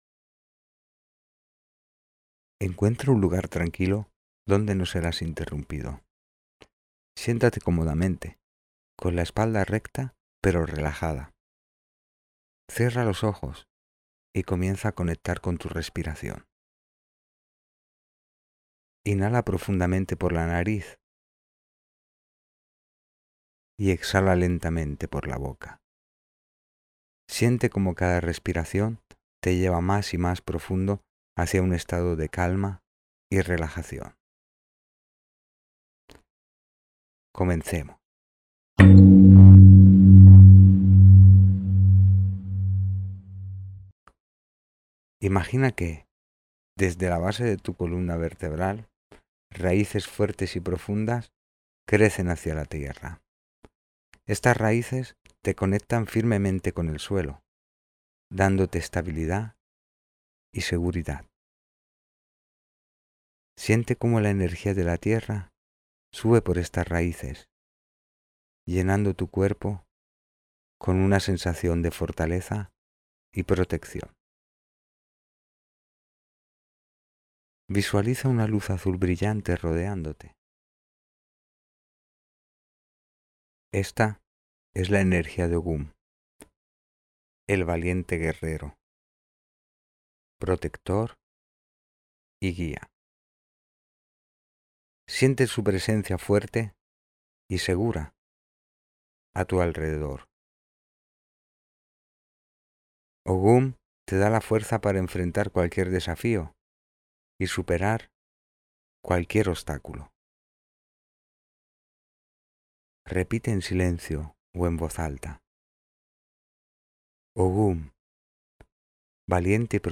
Meditación guiada de respiración (6 minutos)